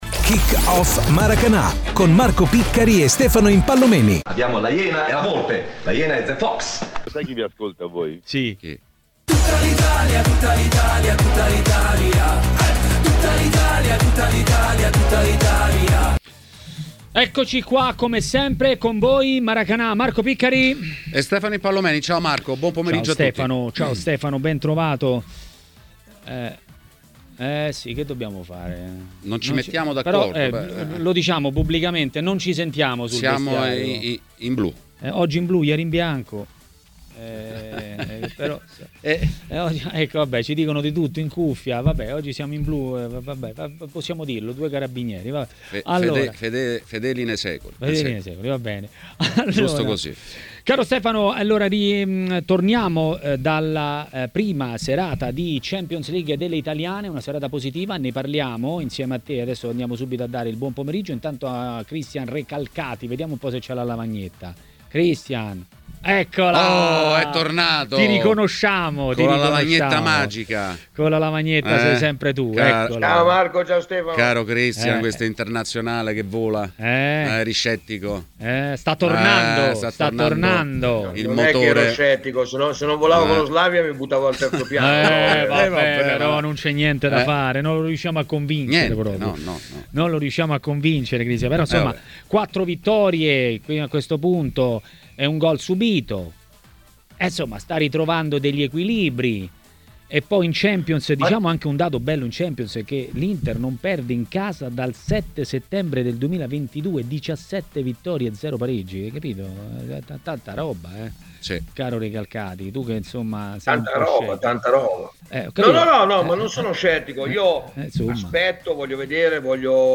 giornalista ed ex calciatore, ha parlato dei temi del giorno a TMW Radio, durante Maracanà.